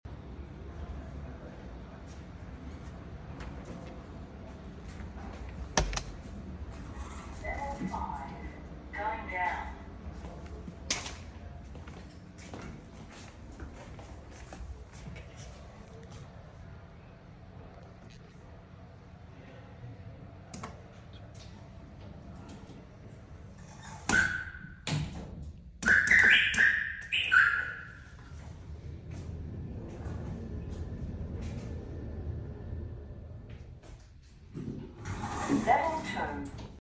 A bird elevator lift alarm sound effects free download
A bird elevator lift alarm at RMIT building 10